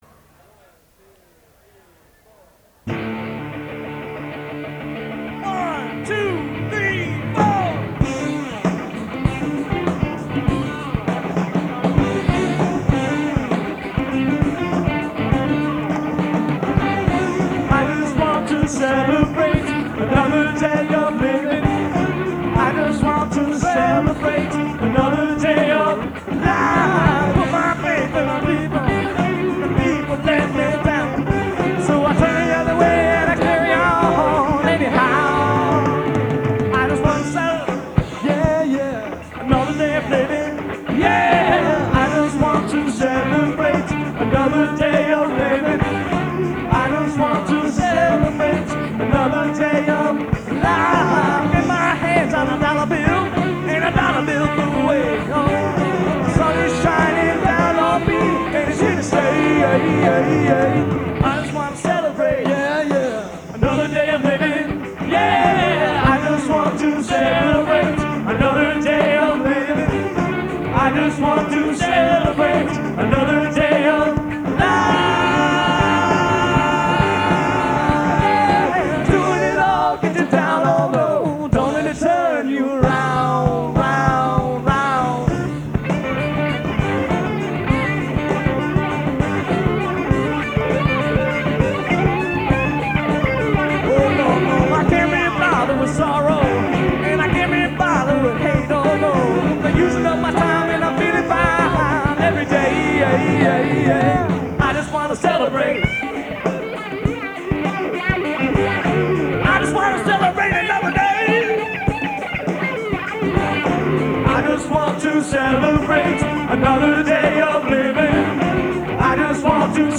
Live Recordings
August 17, 1996 - Shenanigan's Nite Club